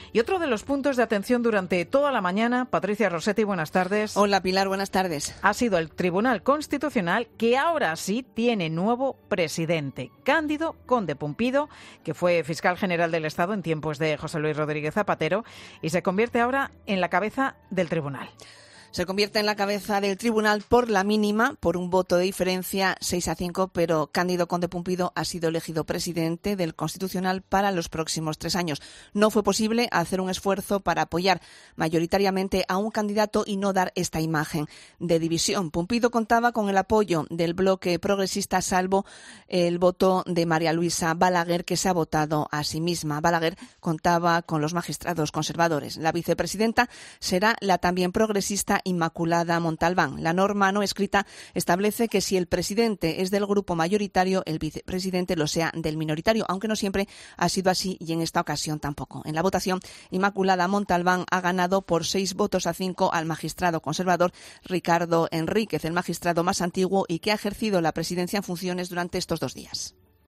ESCUCHA AQUÍ EL ANÁLISIS EN 'MEDIODÍA COPE': ¿Qué ha pasado en las votaciones en las que ha salido elegido Conde-Pumpido?